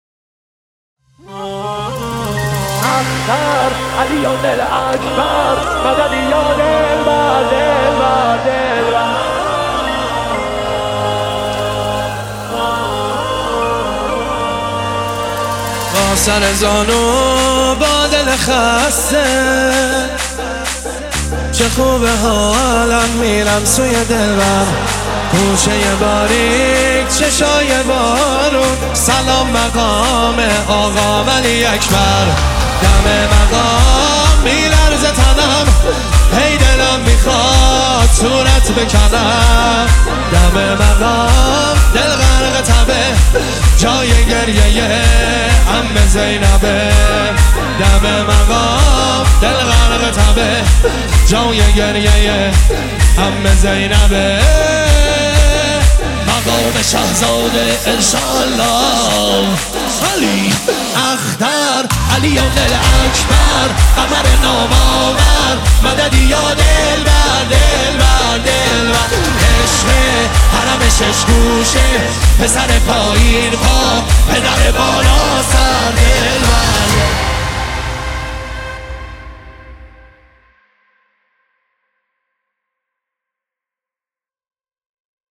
مداحی های منتخب
شب عاشورا